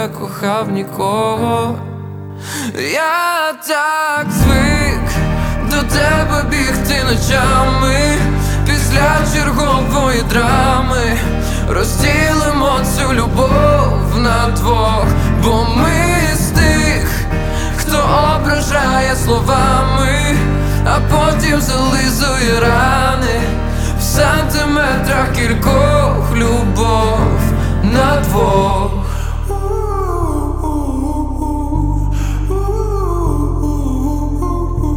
Pop Alternative